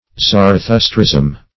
Zarathustrism \Zar`a*thus"trism\, n.
zarathustrism.mp3